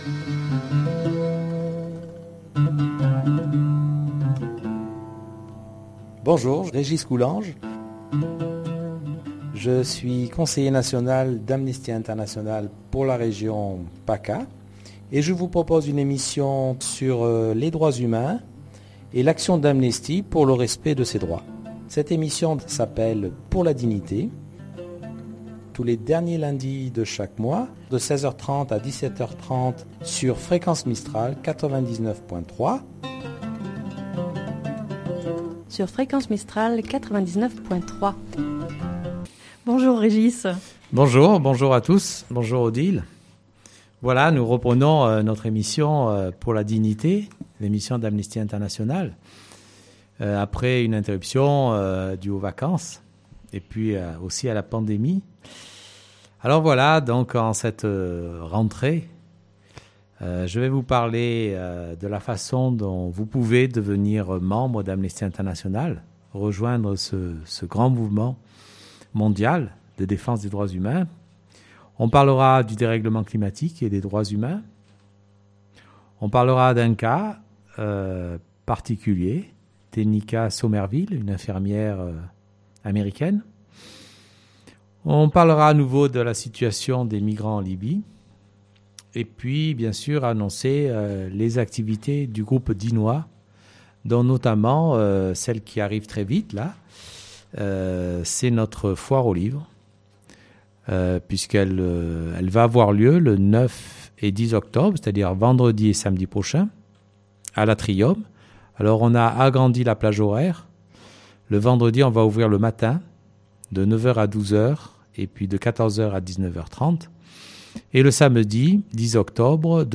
Une émission proposée, préparée et animée